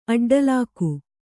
♪ aḍḍalāku